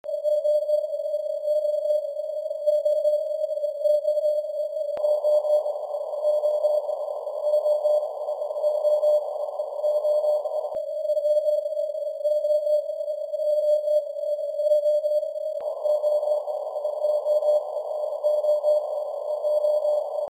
Simulated signals and simulated noise are used in order to have controlled signal levels and frequencies.
In the next four samples a 50Hz bandwidth brick-wall filter and a 25Hz bandwidth variable CW filter are used during four 5 seconds intervals. The first and third 5 seconds are for the brick-wall filter and the second and fourth 5 seconds are for the variable filter.
In the test signal two CW-signals are hidden in the noise (the letter “O”, three dashes), one at 600Hz and one at 750Hz.
bw_var_bw_var_600Hz_highknee (476kB)
-        High AGC knee voltage / no AGC action: this really improves readability. A narrow brick-wall filter gives only slightly less readable signals than the variable CW-filter.
Audio quality: these are compresses files (mp3)